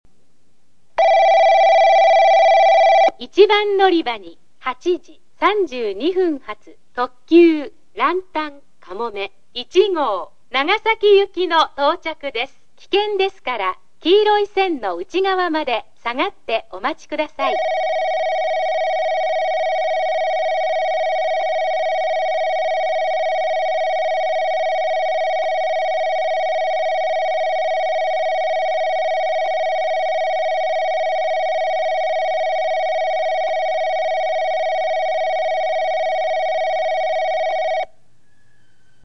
放送は「九州主要放送」導入駅のみ。
二日市　１番線案内放送（ランタンかもめ号）